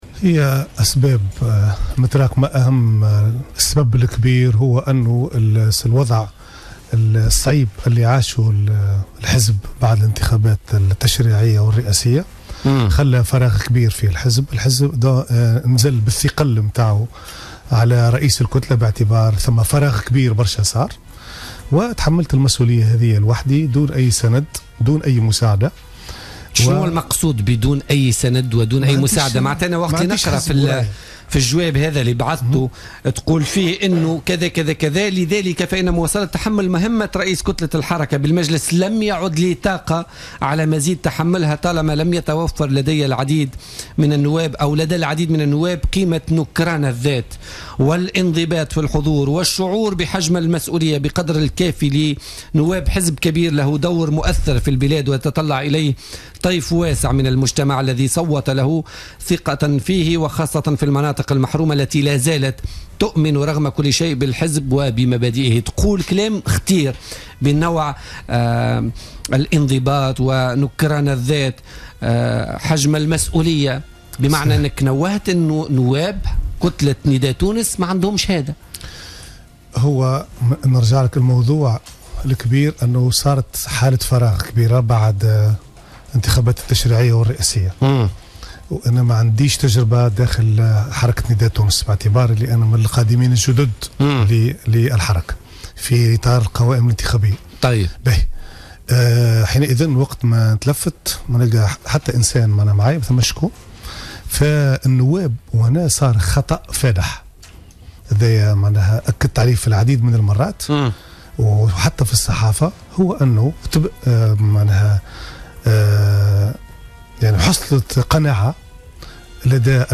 كشف الفاضل بن عمران رئيس كتلة نداء تونس بمجلس نواب الشعب سابقا وضيف برنامج بوليتكا لليوم الأربعاء 18 ماي 2016 عن الأسباب الحقيقية التي دفعته مؤخرا لتقديم استقالته.